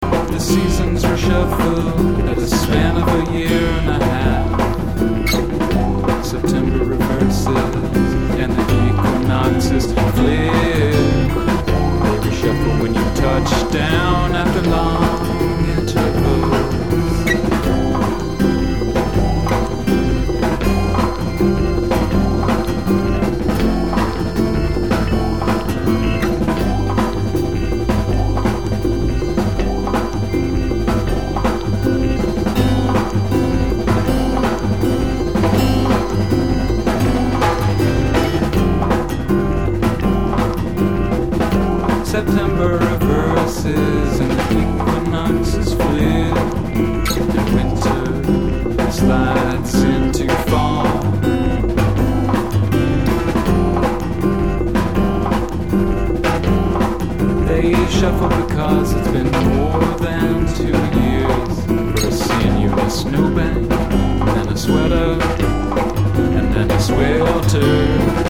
Catalogado quase sempre como pós-rock